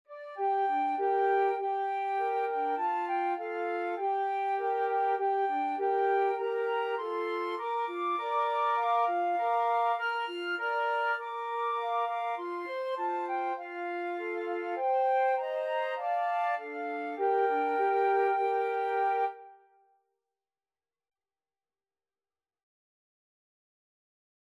Free Sheet music for Flute Trio
2/4 (View more 2/4 Music)
Moderato
Bb major (Sounding Pitch) (View more Bb major Music for Flute Trio )
Traditional (View more Traditional Flute Trio Music)